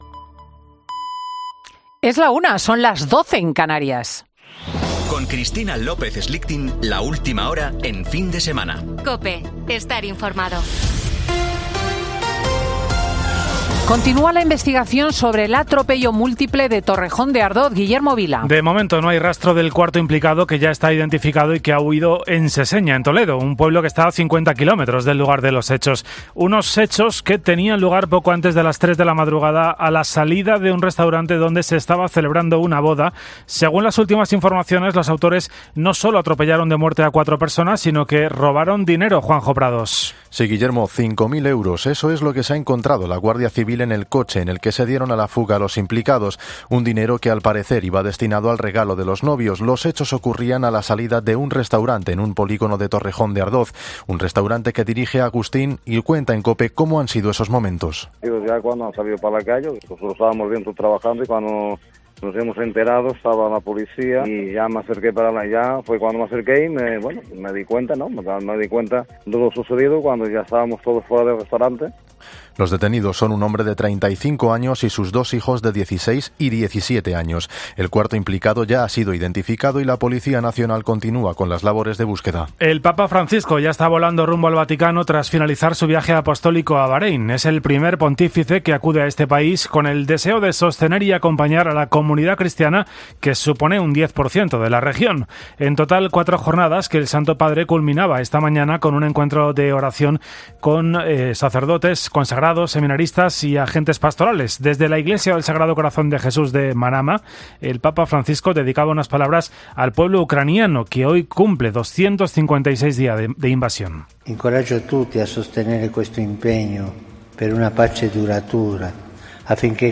Boletín de noticias de COPE del 6 de noviembre de 2022 a las 13.00 horas